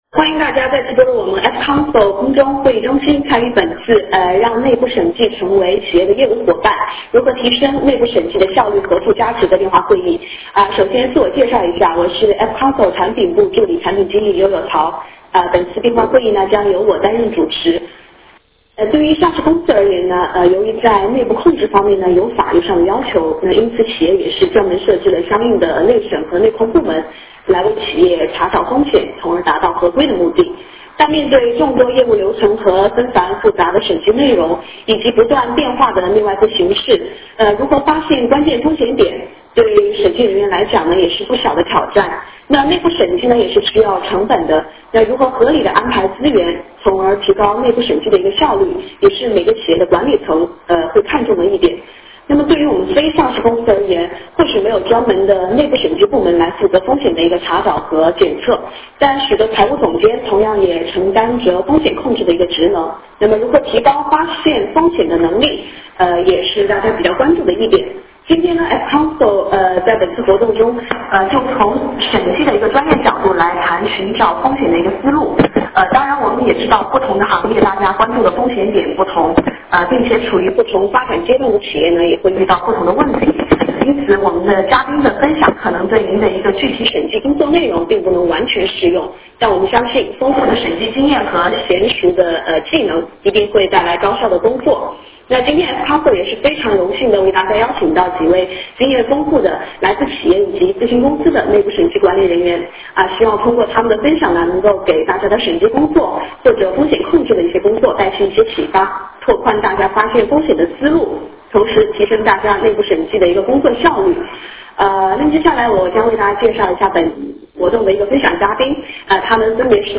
电话会议
本次电话会议F-Council力邀企业资深内部审计经理和咨询公司风险管理专家在分享内部审计专业经验的同时为企业财务高管拓宽发现风险的思路，让您的财务管理工作更具价值！